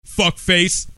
Category: Comedians   Right: Commercial
Tags: comedy announcer funny spoof crude radio